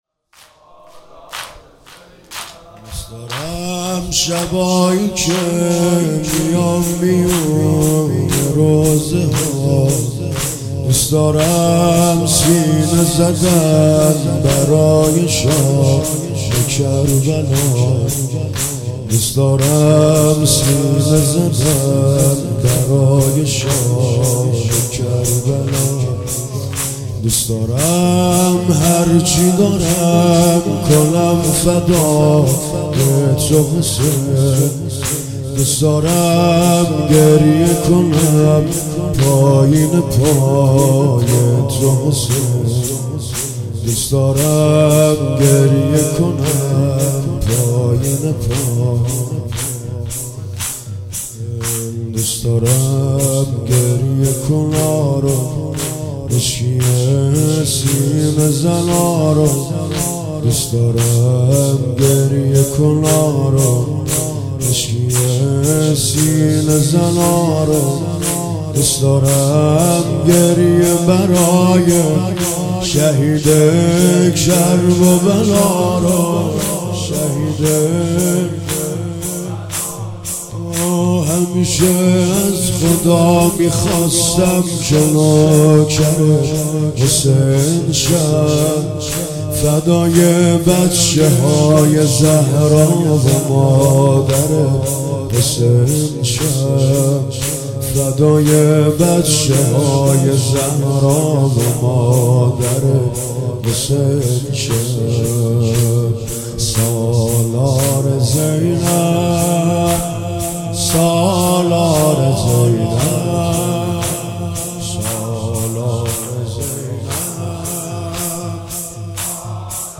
Madahi